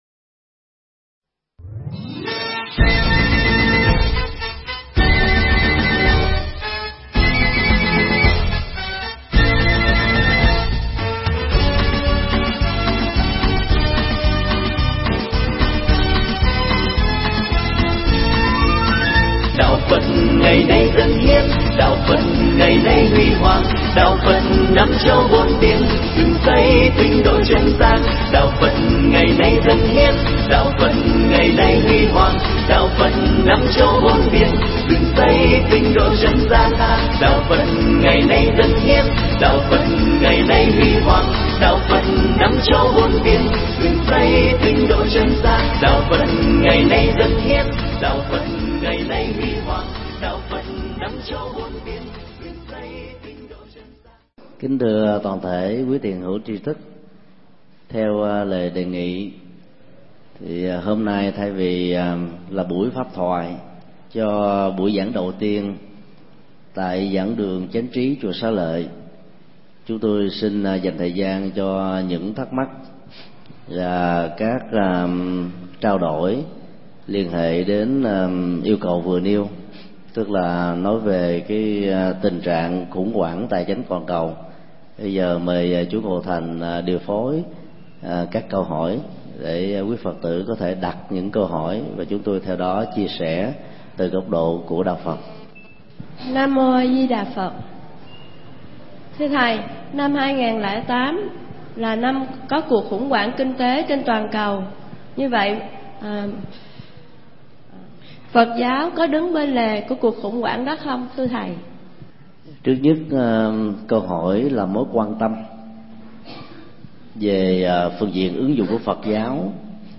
Nghe mp3 pháp thoại Phật Giáo Và Khủng Hoảng Tài Chính
giảng tại Chùa Xá Lợi